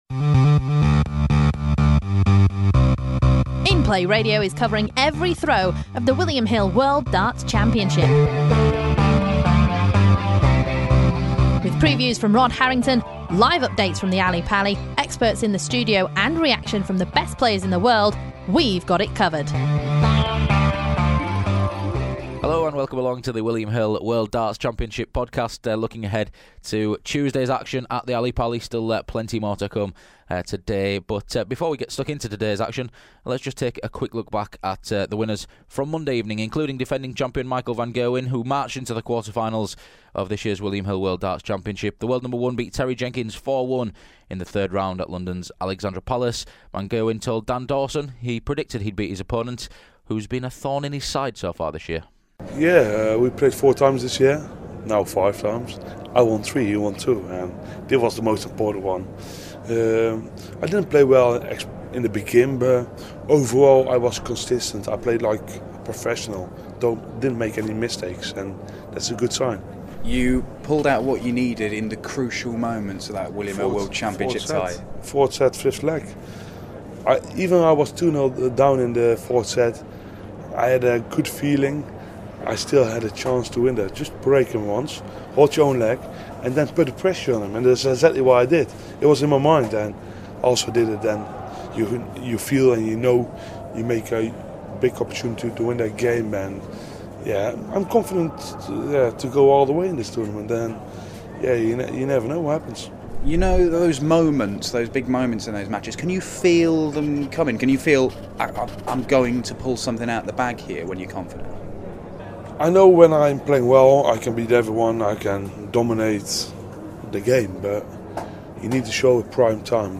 Reigning champion Michael van Gerwen marched into the quarter-finals on Monday evening and we hear from him and the other winners on the night.